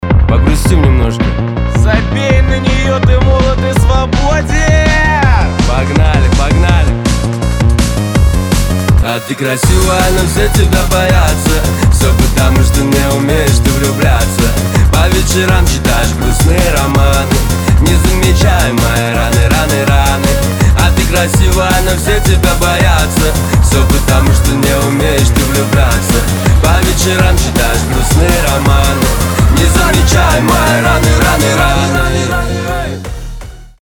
• Качество: 320, Stereo
мужской вокал
громкие
быстрые
drum n bass
Hardstyle
Hard dance